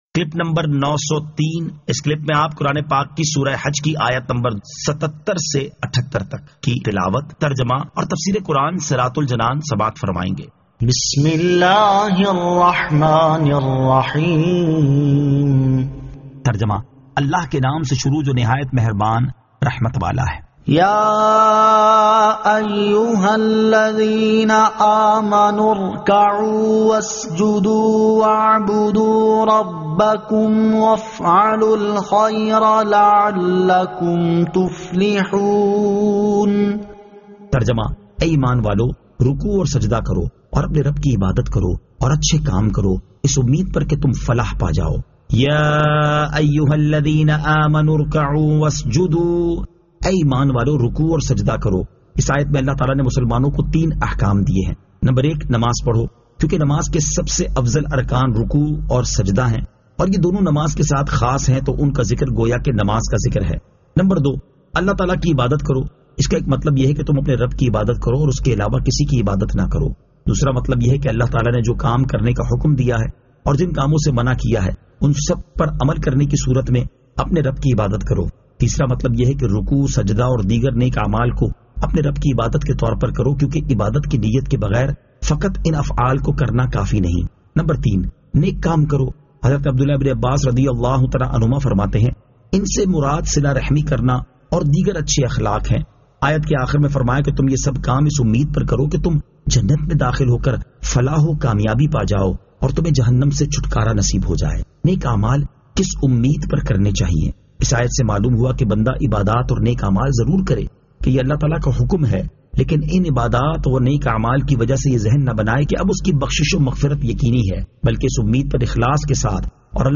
Surah Al-Hajj 77 To 78 Tilawat , Tarjama , Tafseer